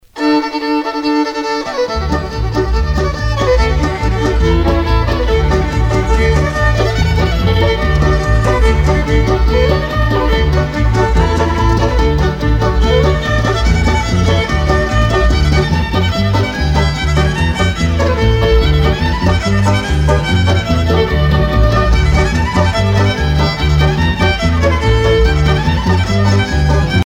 danse : pas d'été
Pièce musicale éditée